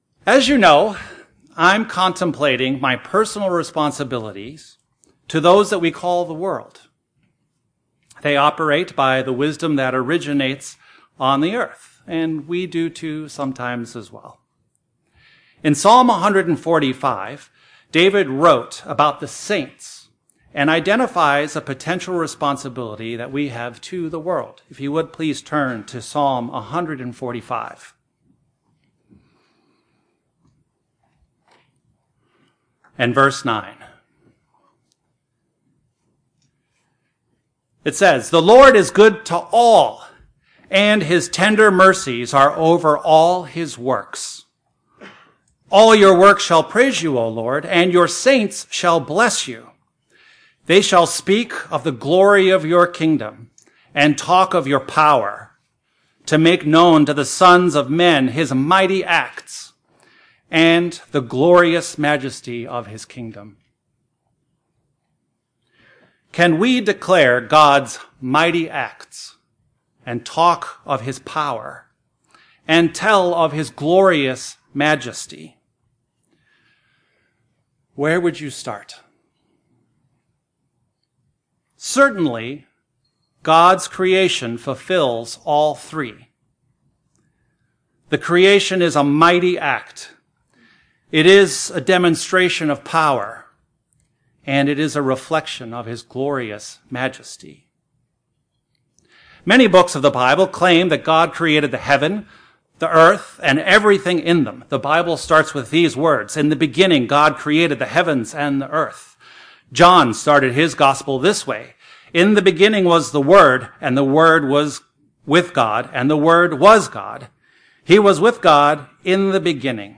This sermon is intended to Increase our confidence to proclaim God’s glory, His might acts, His power and tell His glorious majesty.